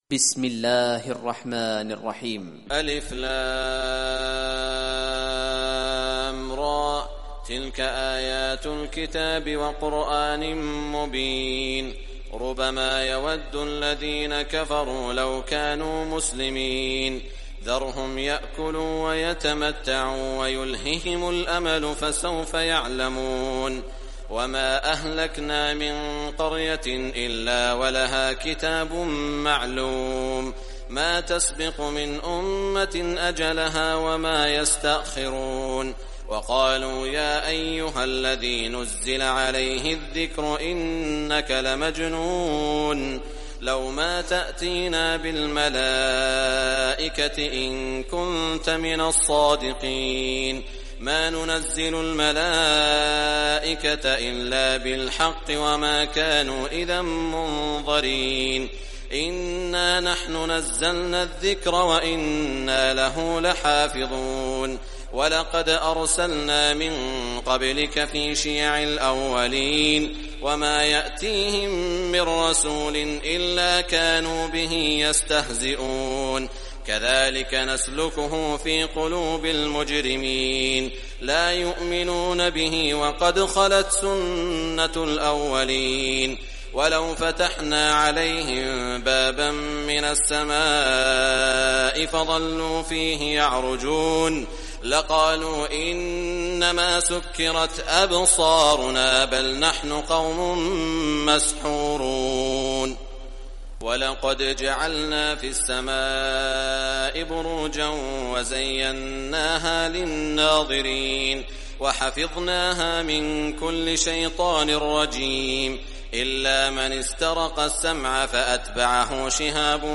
Surah Al-Hijr Recitation by Sheikh Shuraim
Surah Al-Hijr, listen or play online mp3 tilawat / recitation in Arabic in the beautiful voice of Sheikh Saud Al Shuraim.